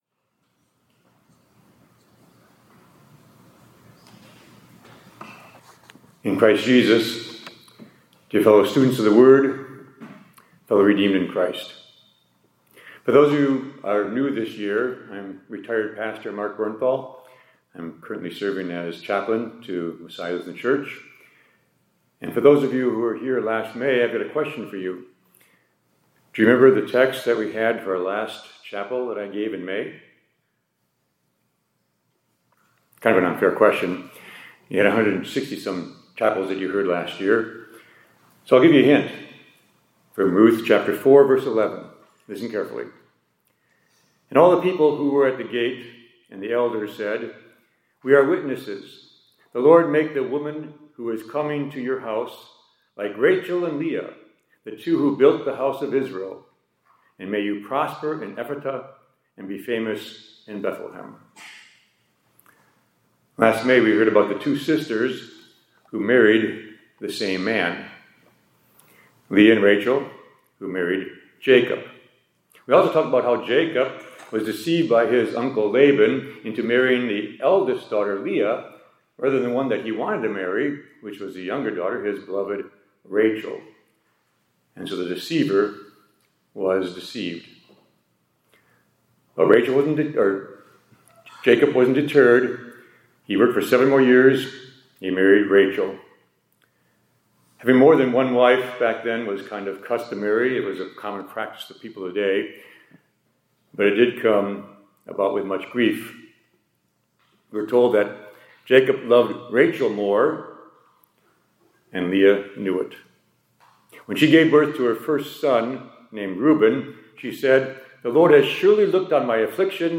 2025-10-03 ILC Chapel — No Family is Beyond the…